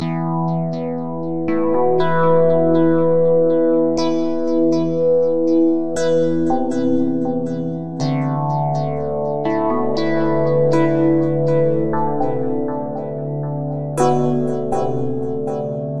描述：反思、寻找、安静、电子
Tag: 120 bpm Ambient Loops Synth Loops 2.69 MB wav Key : Unknown